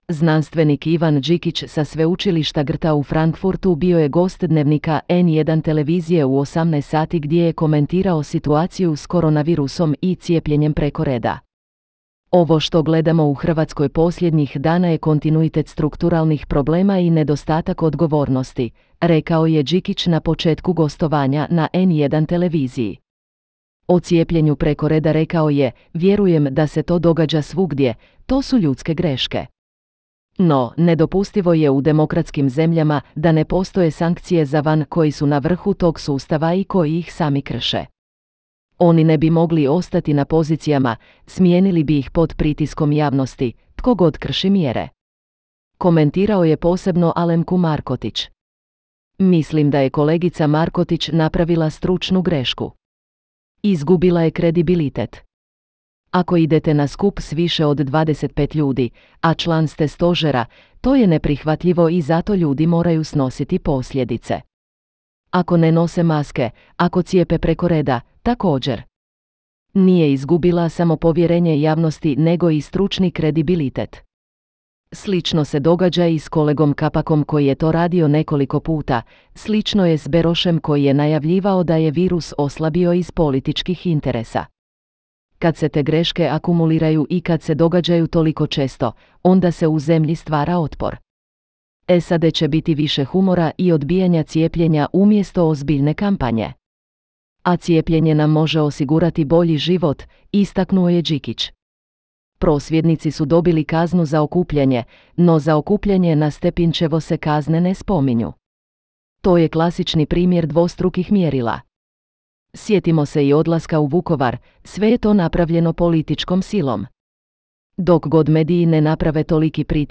zenski_sgp0jarh-Index.wav